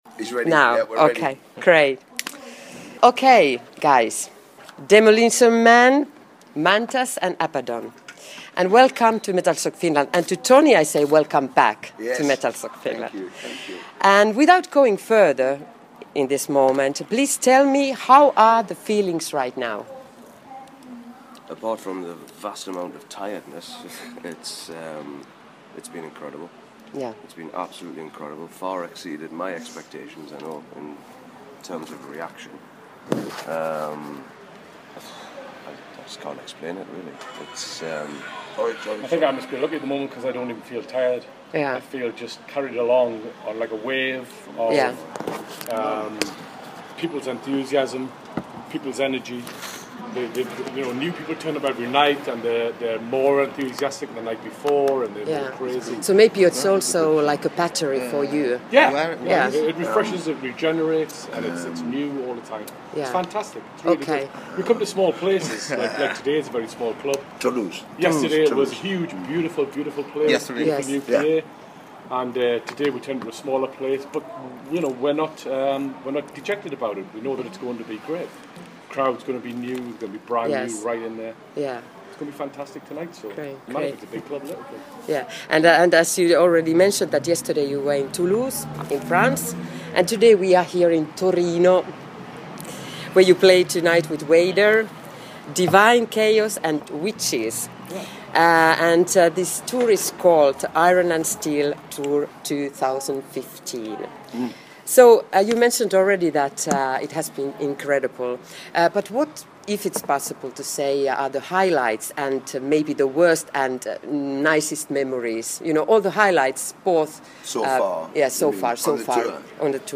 Interview
Tony “Demolition” Dolan, Jeff “Mantas” Dunn and Tony “Abaddon” Bray spoke about the highlights and how they’re finding life on this tour.